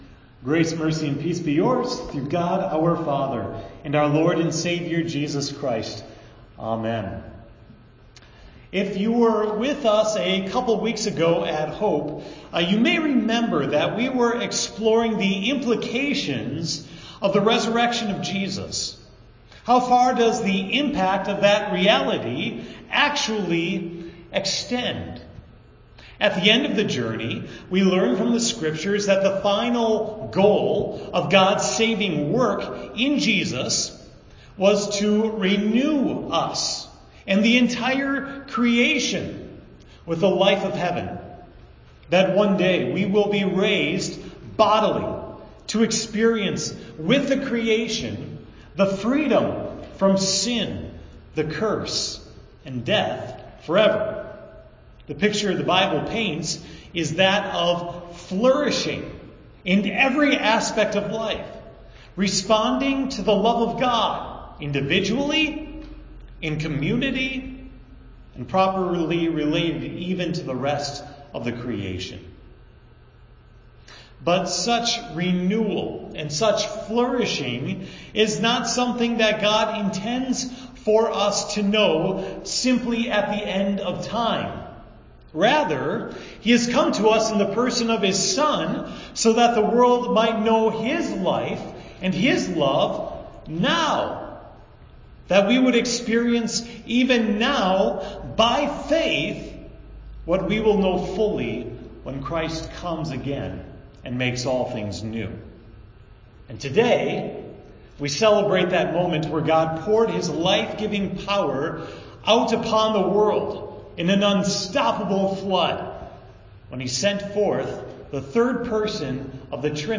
The sermon for Pentecost Sunday, May 20, 2018 at Hope Texts: Ezekiel 37:1-14, Acts 2